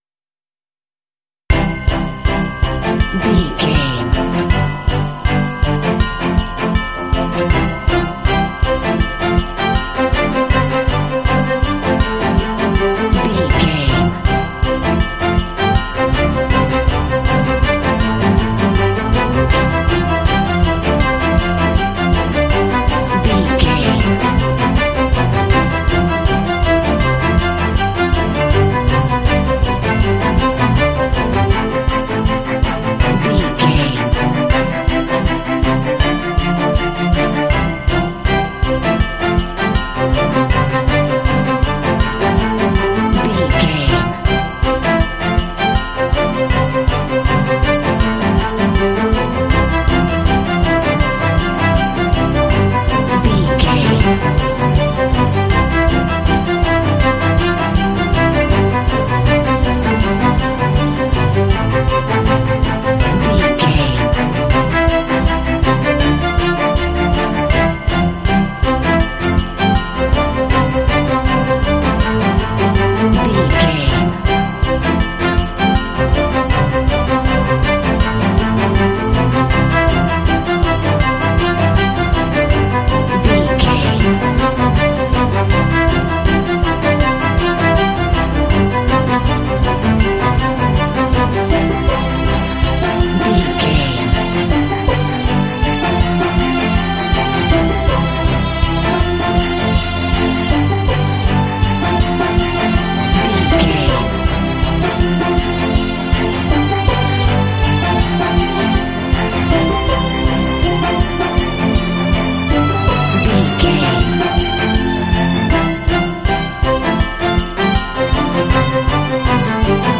Uplifting
Ionian/Major
cheerful/happy
joyful
driving
strings
percussion
cinematic